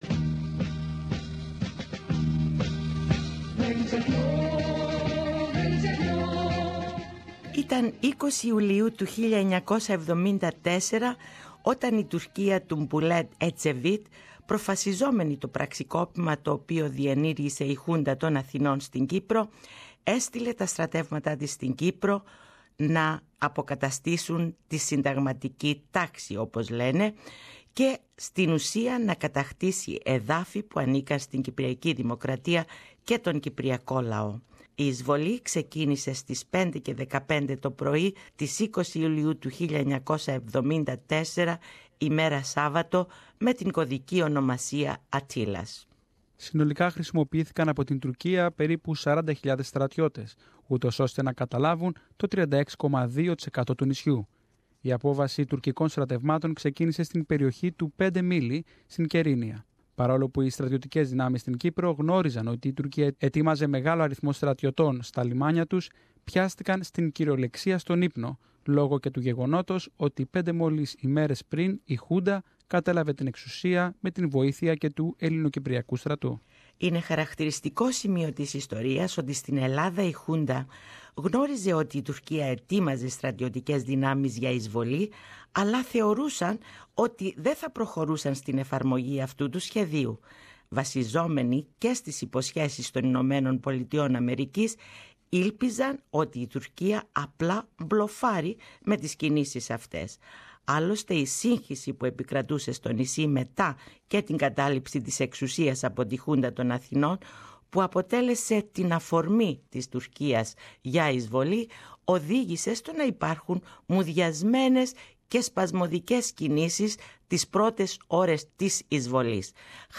SBS's Greek program has prepared a special report about the invasion of 1974.